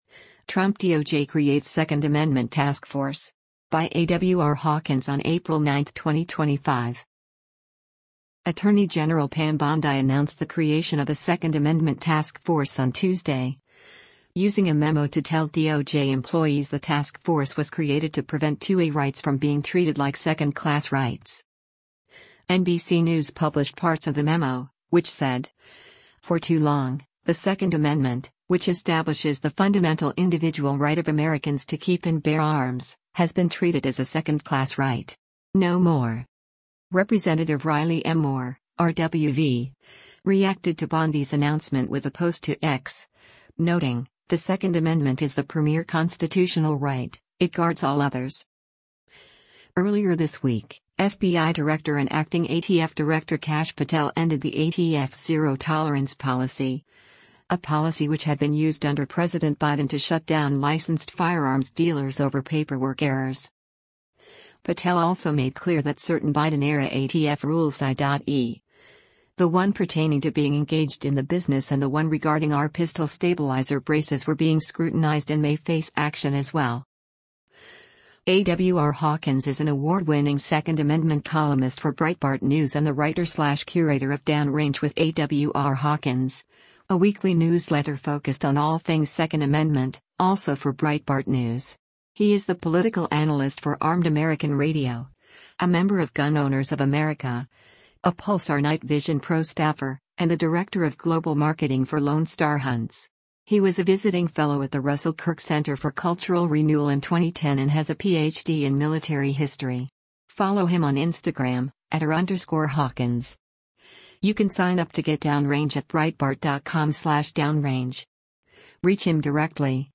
Attorney General Pam Bondi speaks before President Donald Trump at the Justice Department